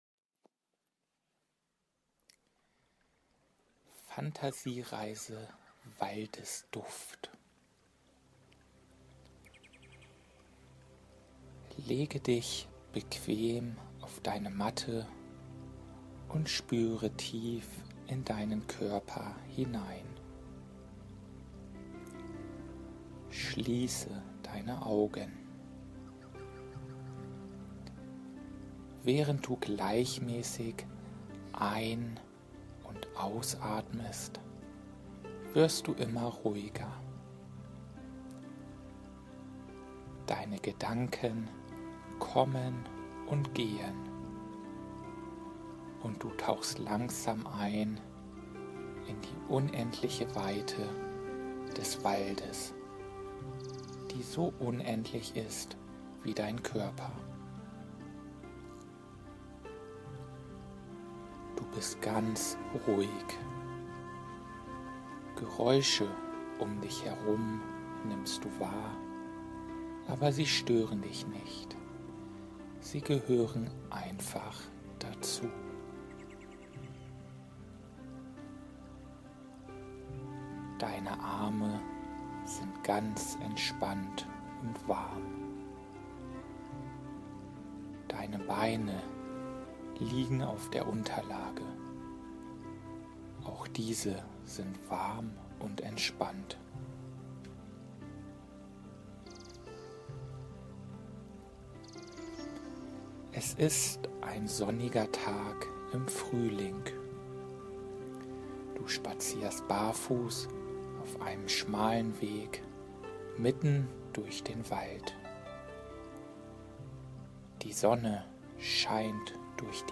fantasiereise-frc3bchlingserwachen-im-wald-mit-musik.mp3